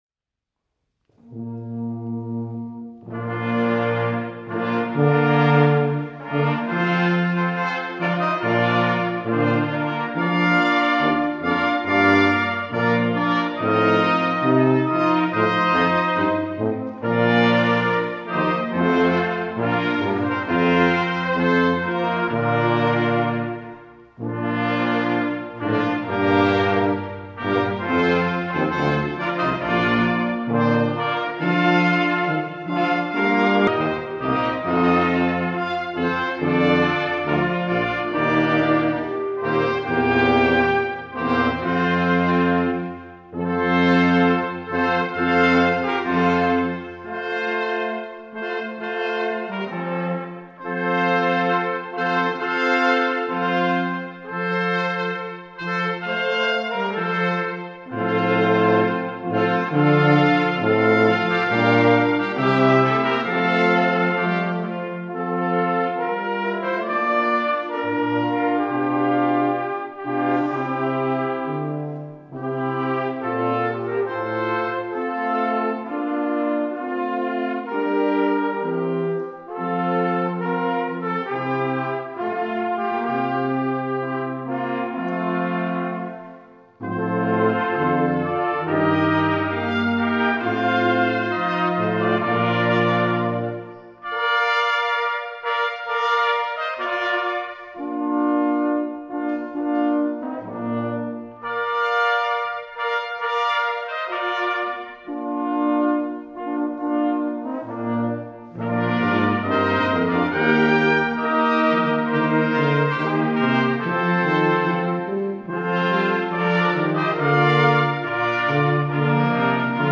Livemitschnitt vom Kirchenkonzert 2006